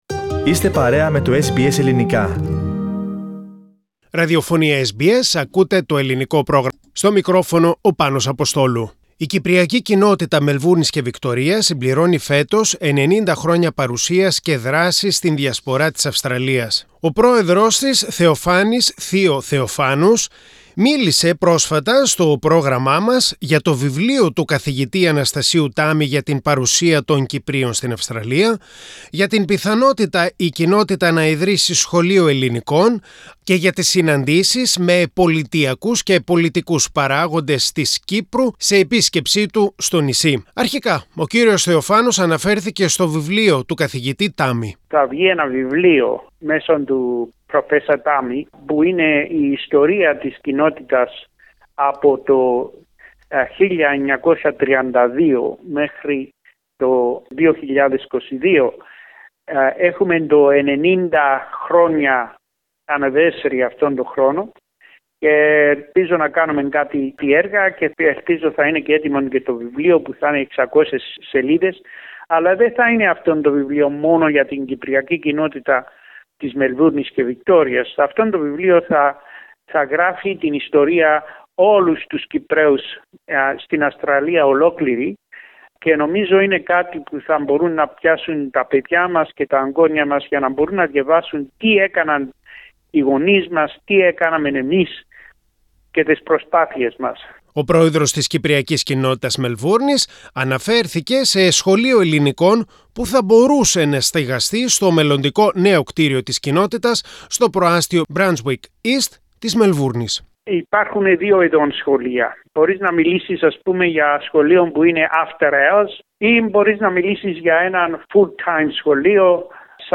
My vision for the Cypriots of Melbourne: CCMV president and former Vic minister Theo Theophanous talks to SBS Greek.